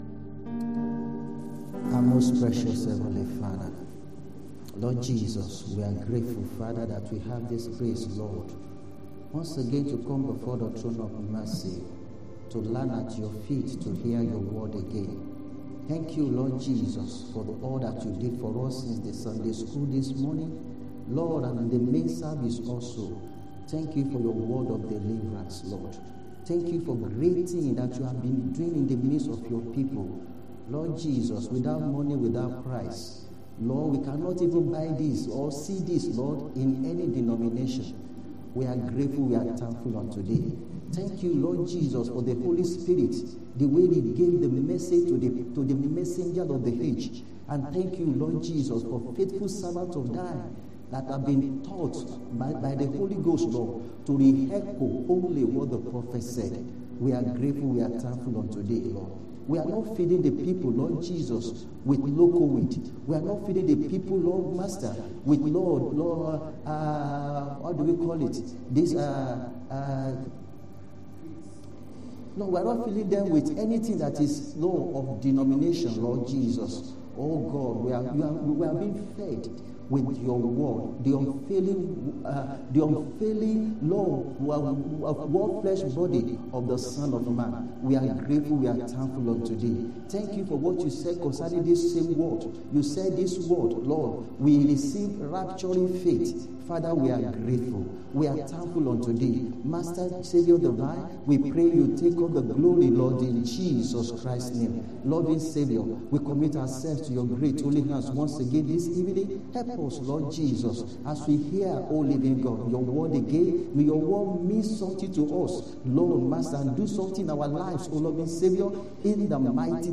Sunday Afternoon Service 13/10/24